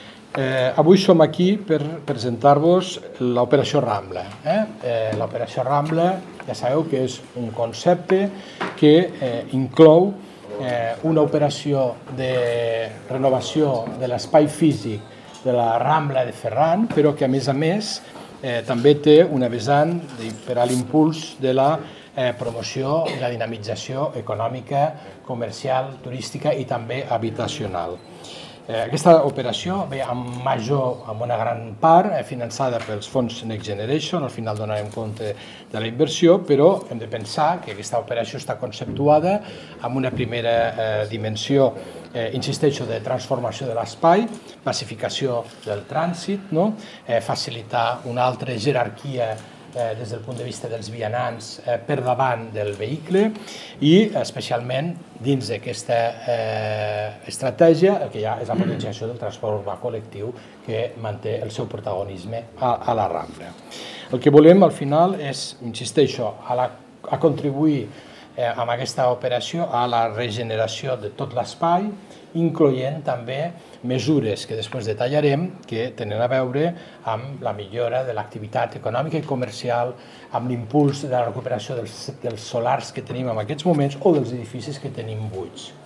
Tall de veu del paer en cap, Fèlix Larrosa